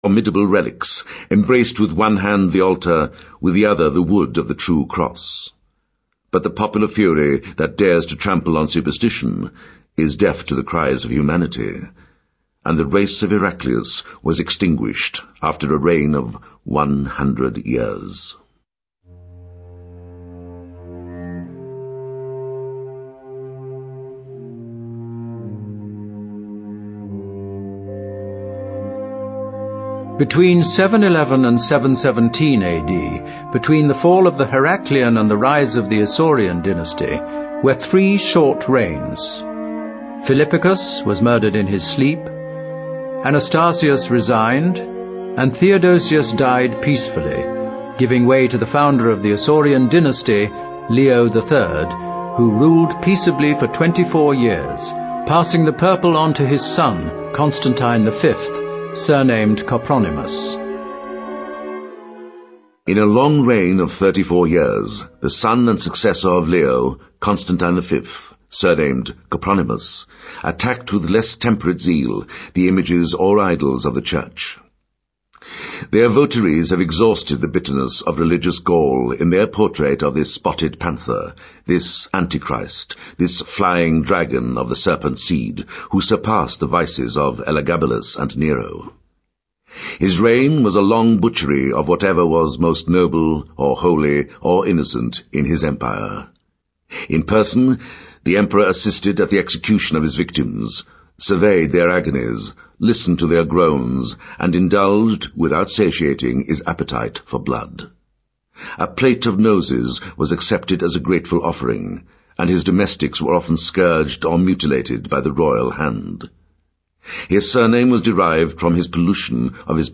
在线英语听力室罗马帝国衰亡史第二部分：39的听力文件下载,有声畅销书：罗马帝国衰亡史-在线英语听力室